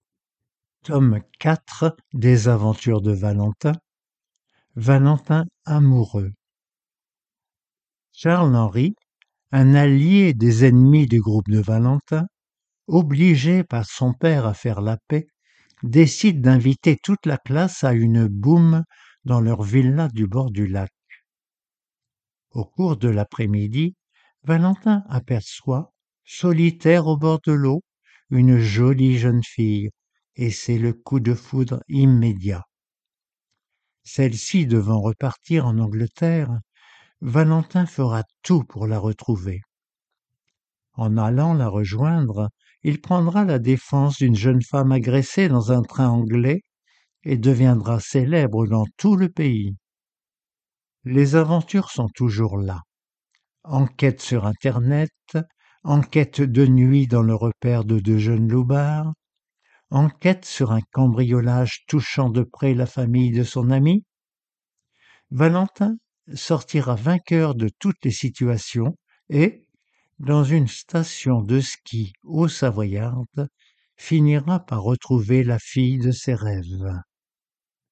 Romans audios pour adultes mal-voyants